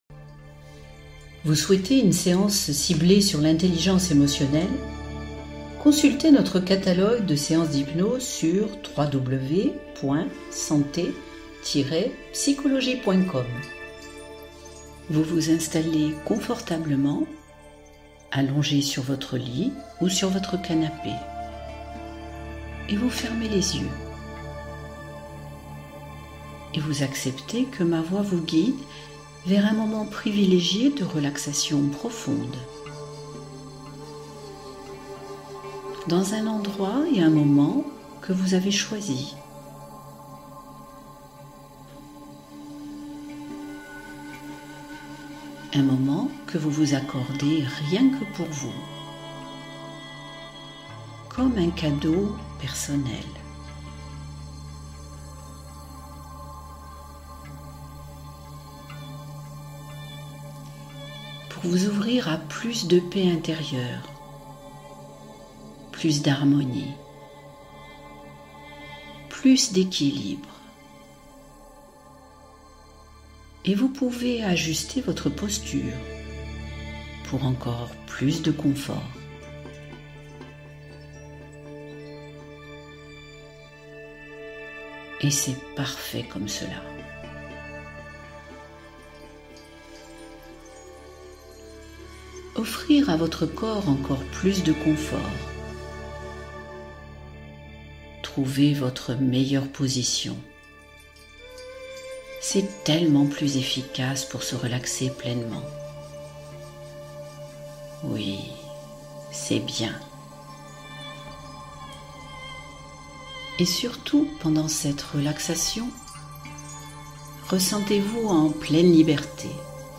Hypnose : libérer les pensées négatives et la lourdeur mentale